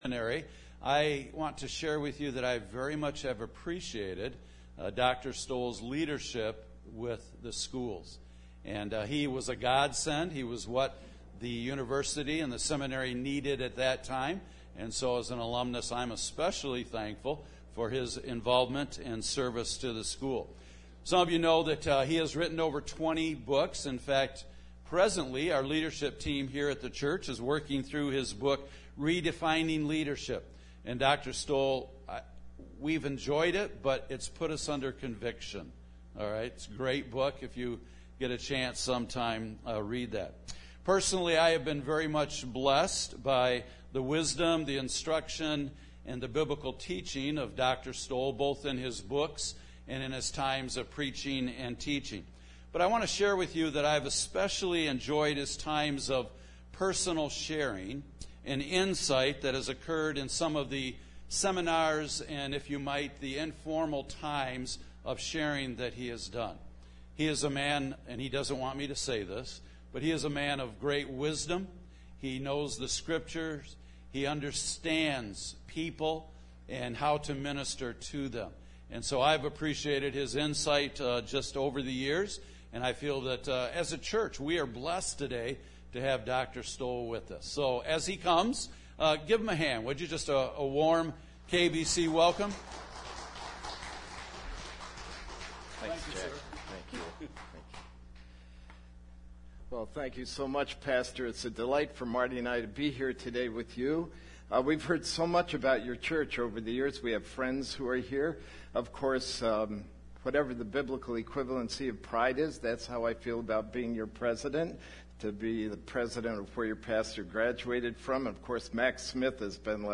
5.23.21 AM Guest Speaker